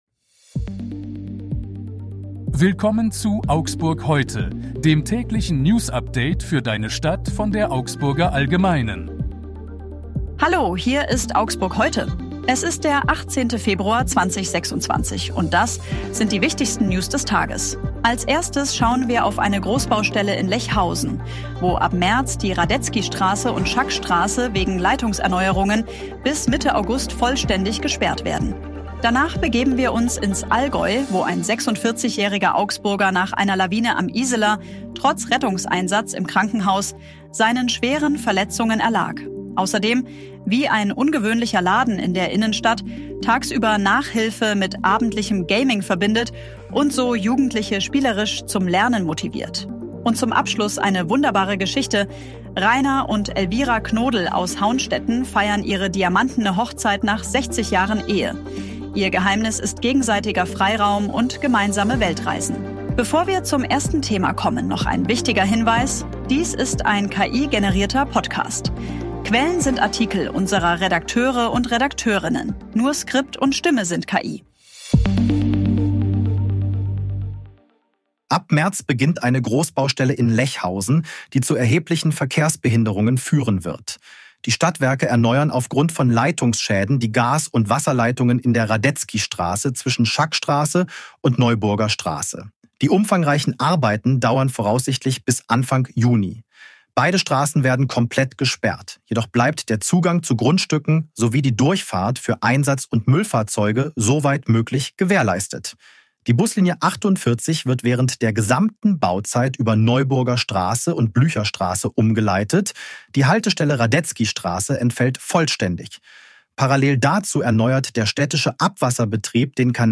Die aktuellen Nachrichten aus Augsburg vom 18. Februar 2026.
Nur Skript und Stimme sind KI.